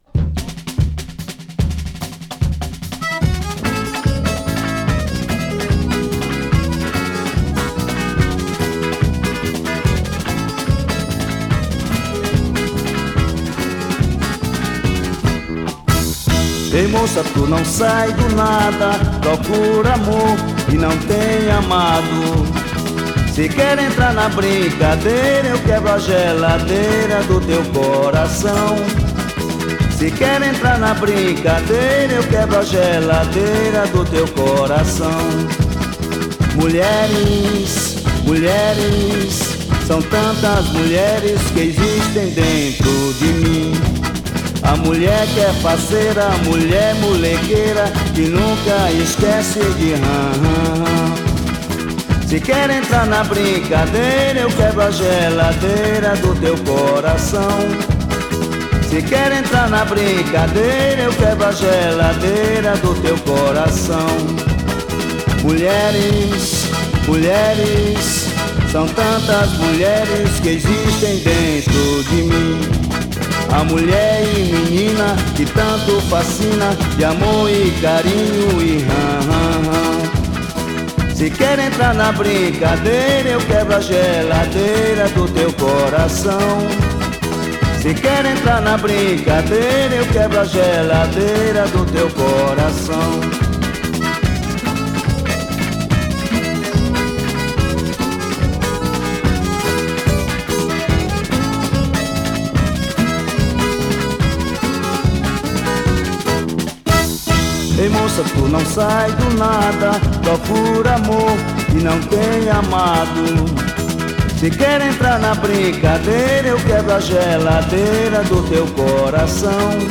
1569   05:02:00   Faixa:     Canção
Craviola, Guitarra, Violao Acústico 6
Acoordeon, Piano Acústico
Baixo Elétrico 6
Bateria
Trompete
Flauta
Sax Alto